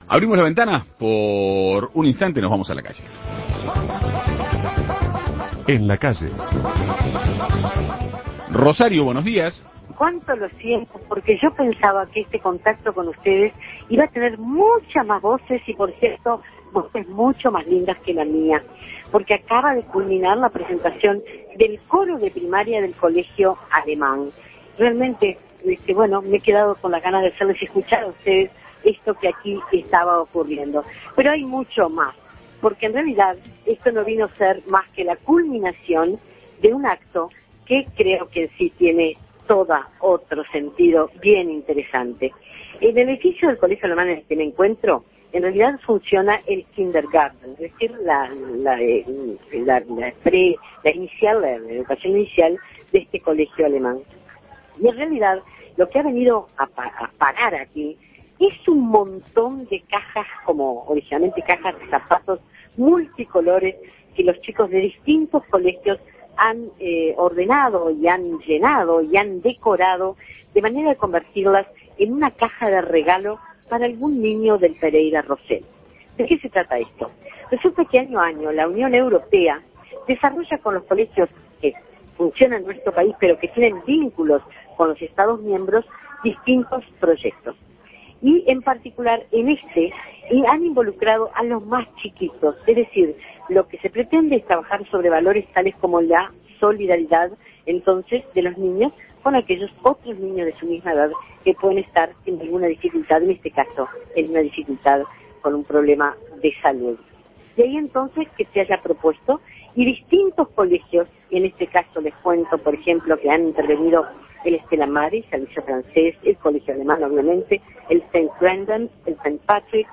Presentación del coro de niños del Colegio Alemán